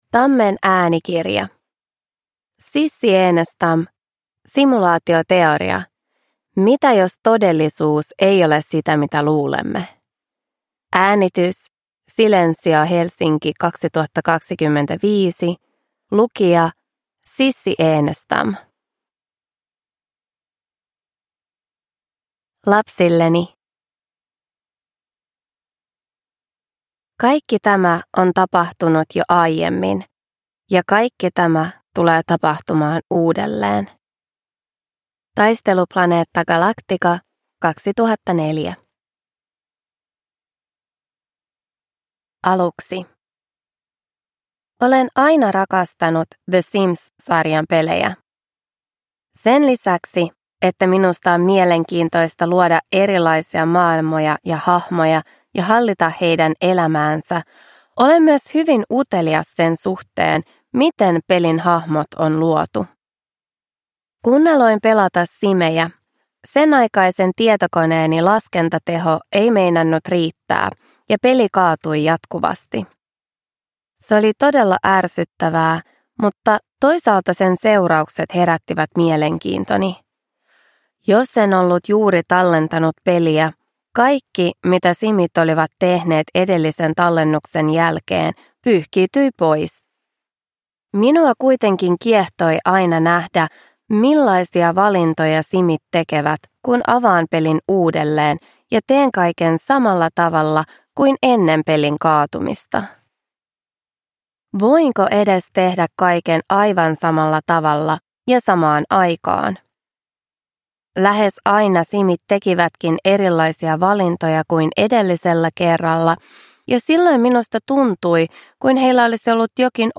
Simulaatioteoria (ljudbok) av Sissi Enestam